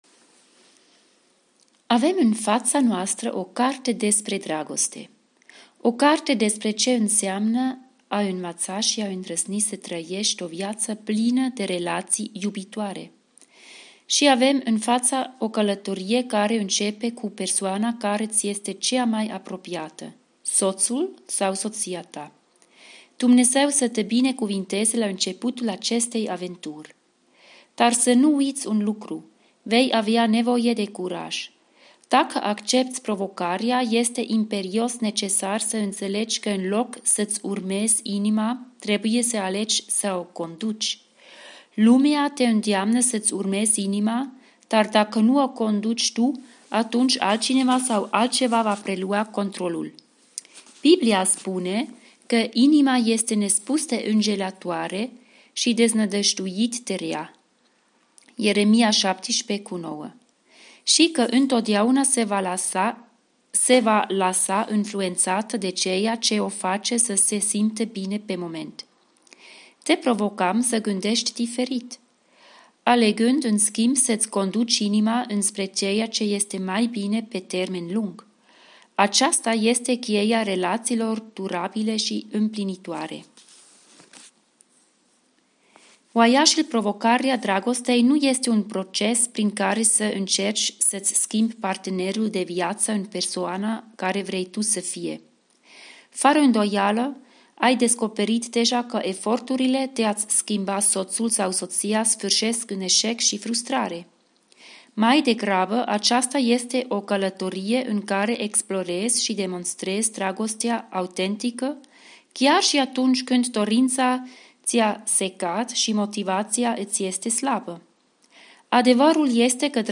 În următorii 40 de podcast-uri vom citi împreună cartea "Provocarea dragostei" de la Stephen & Alex Kendrick.